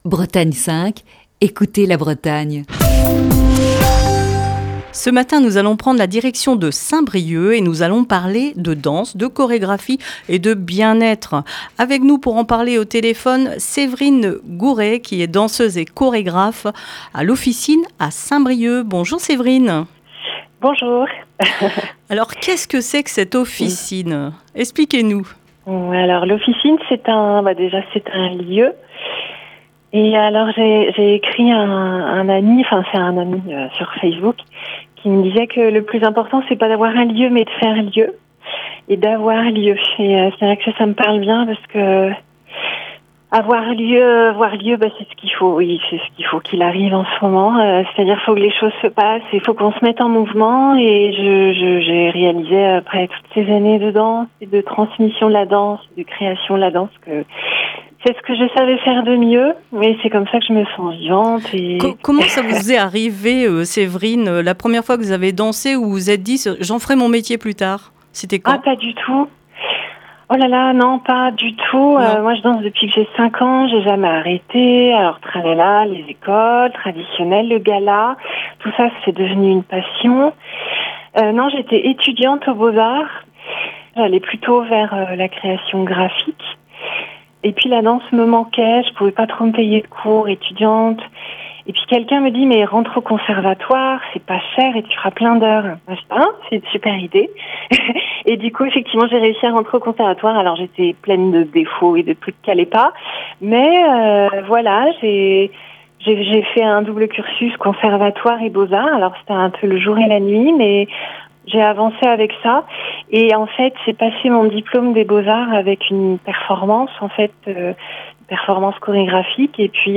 Dans le coup de fil du matin de ce jeudi